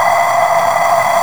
G# VOX NOISE.wav